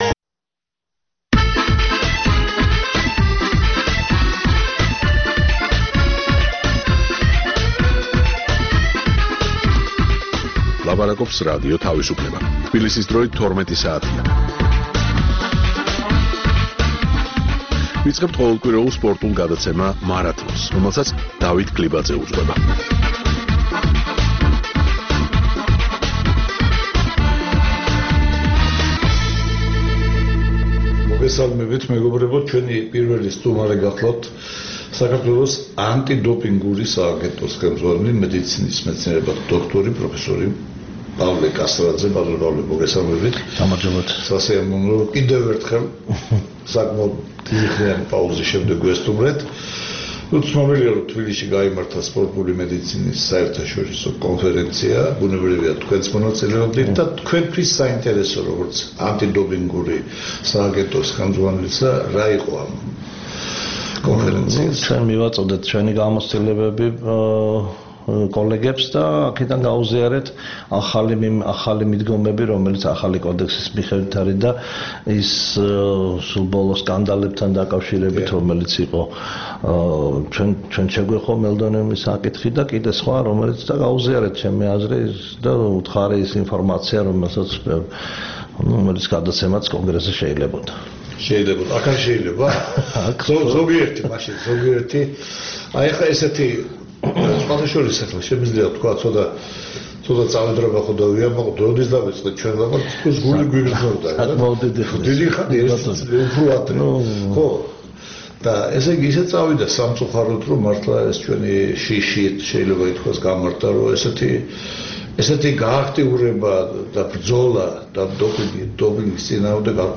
სპორტული პროგრამა "მარათონი"